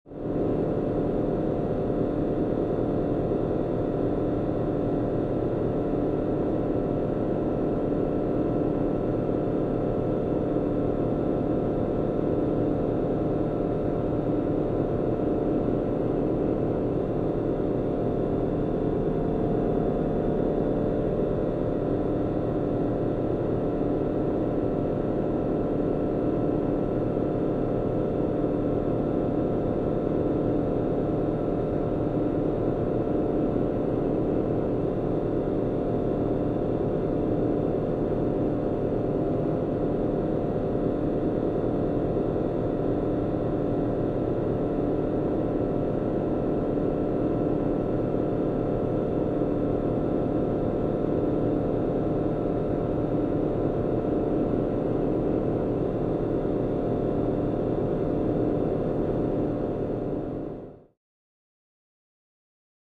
Submarine Ambience
Low Tonal Hum And A / C Noise On Ship Or In Basement.